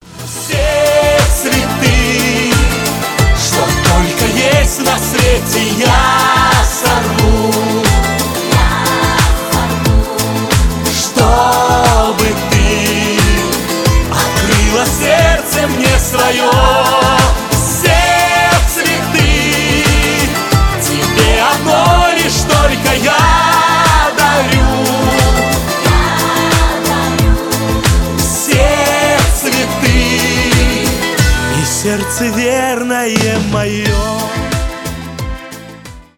поп
эстрадные